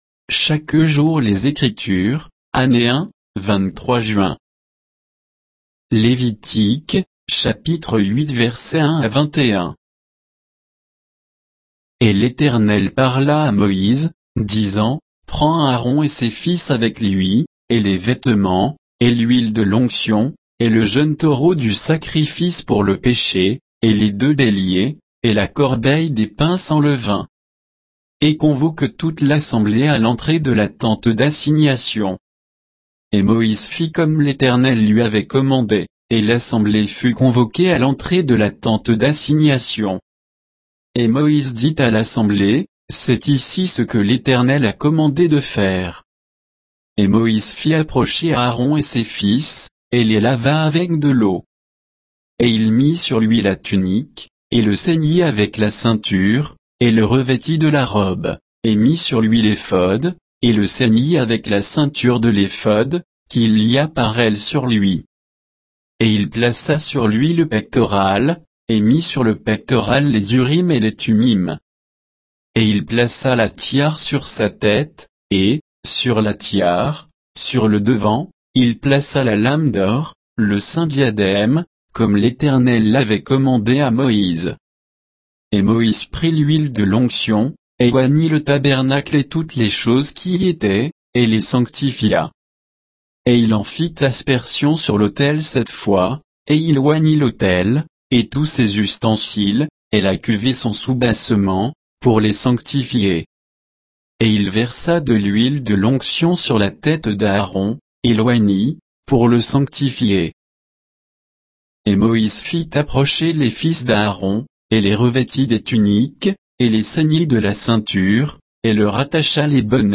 Méditation quoditienne de Chaque jour les Écritures sur Lévitique 8